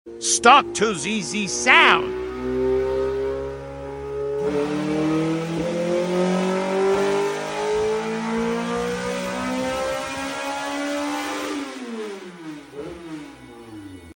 204 whp Fbo pump gas FT ecu